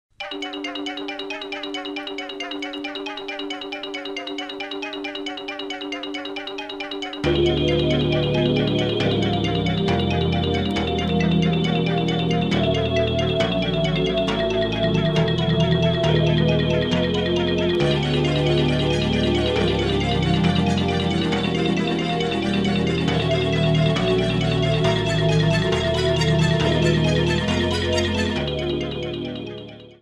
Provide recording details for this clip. Cut off and fade-out